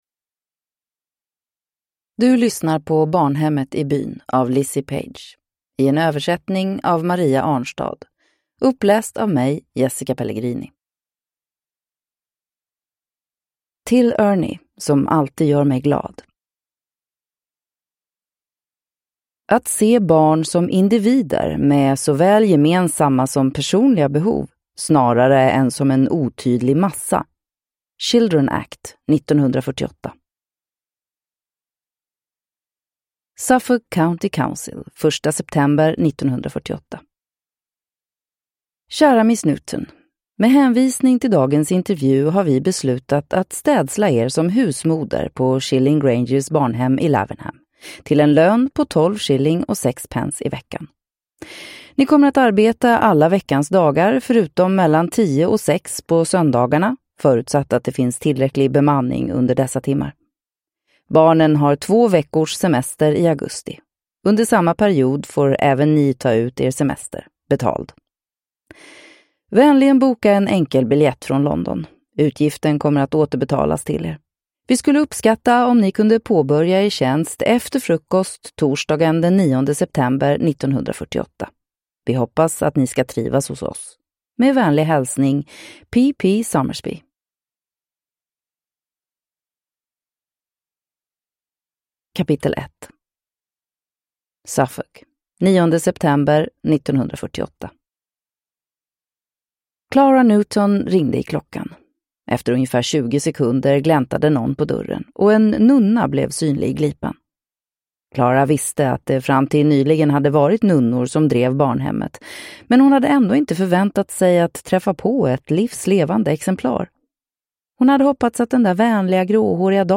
Barnhemmet i byn (ljudbok) av Lizzie Page